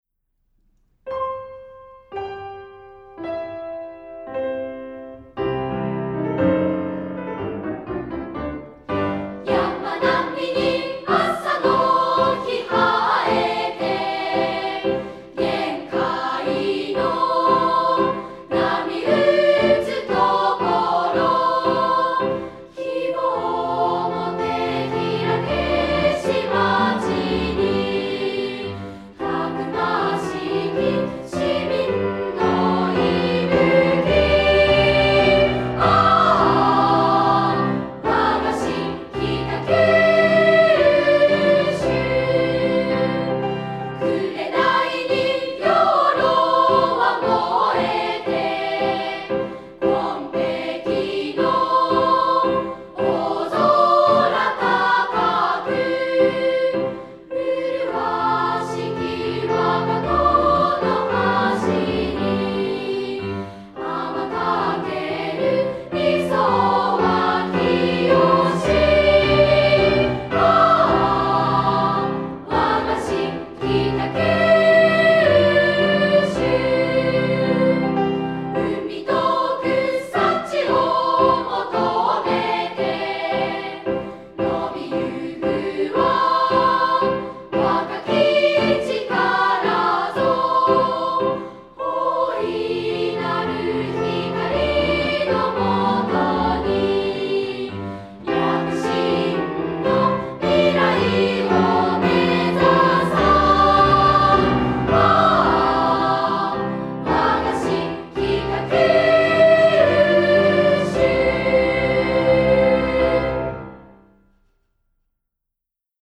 市民の皆様にさらに親しみを持って活用していただけるよう、平成27年度に市歌を録音しなおしました。
（1）　小中学生（北九州市少年少女合唱団）による合唱
（2）　子どもでも歌いやすいようにアレンジした伴奏
北九州市歌（リニューアル版）合唱付（音声ファイルMP3形式：4529KB）